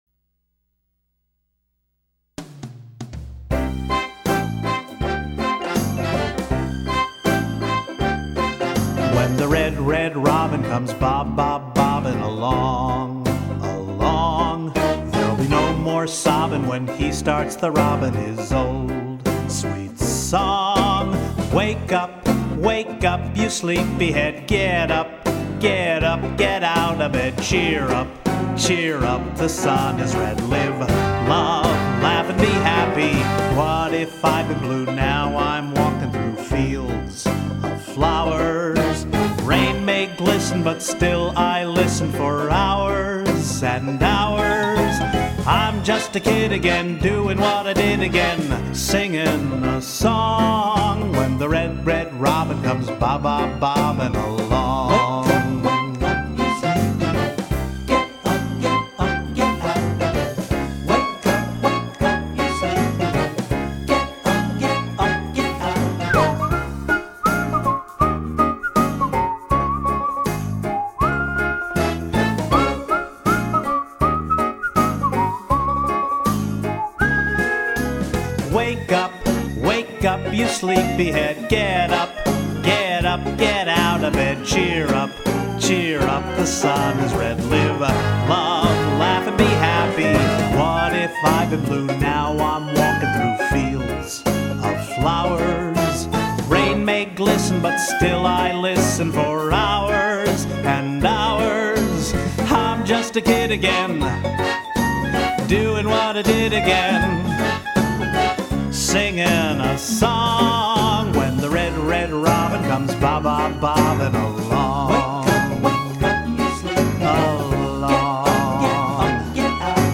Wasn’t that perky as all get out?